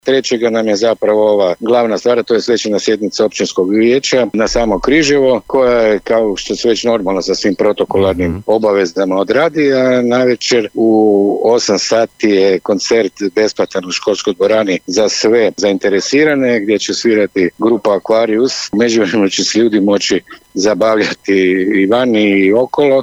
– rekao je u emisiji Susjedne općine, načelnik Općine Rasinja Danimir Kolman.